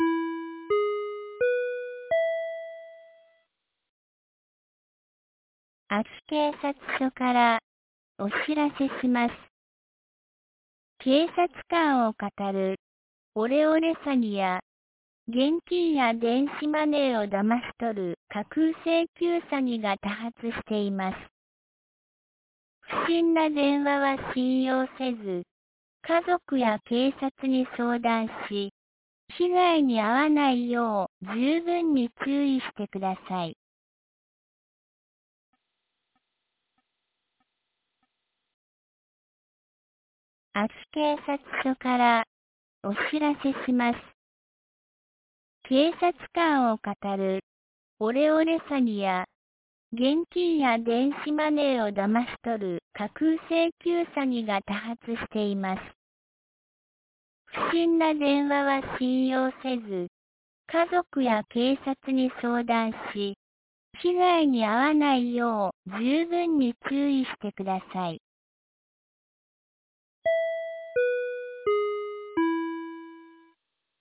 2024年10月24日 09時01分に、安芸市より井ノ口へ放送がありました。